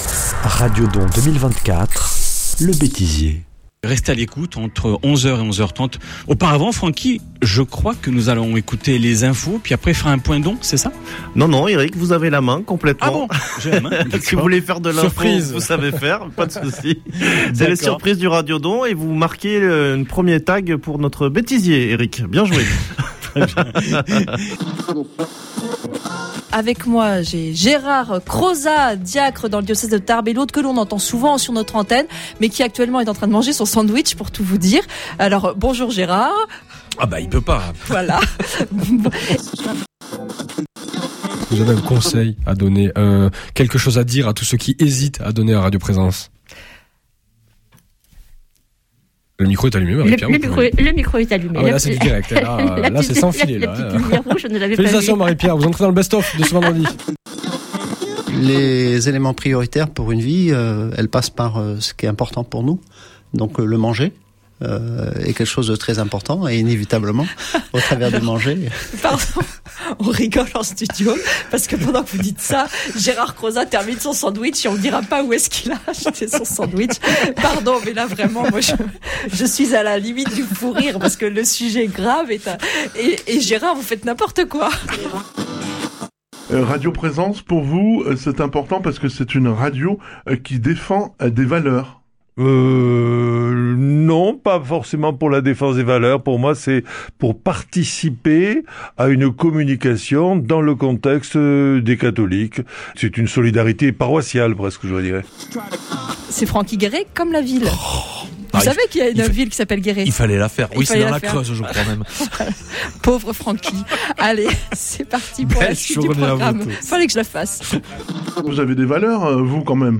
Les moments les plus drôles de ces trois jours de radio don